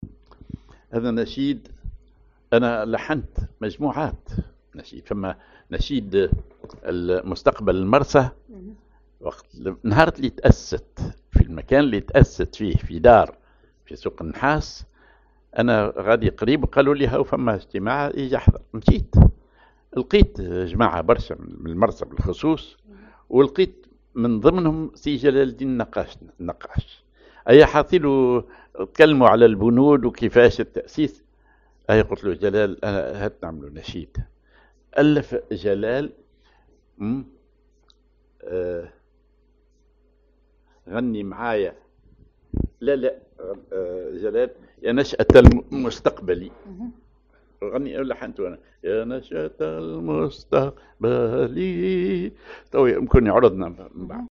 genre نشيد